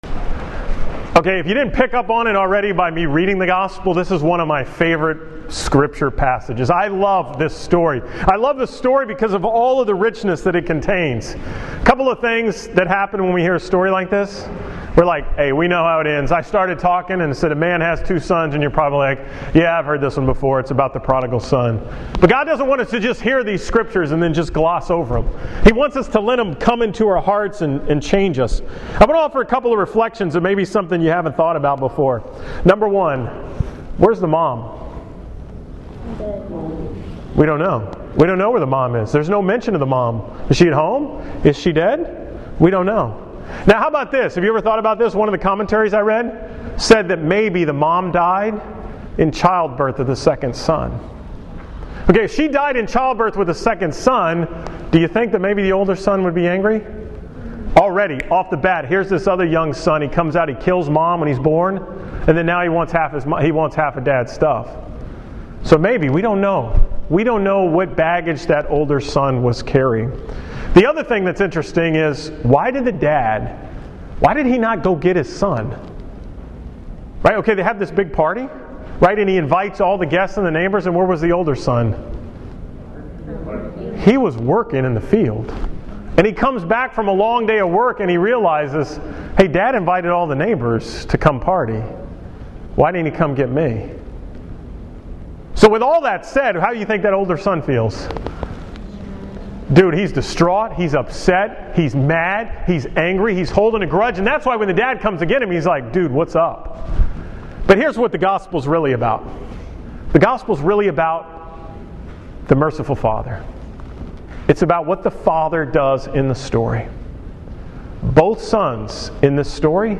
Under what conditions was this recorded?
From the youth retreat at Camp Kappe on Saturday, March 5, 2016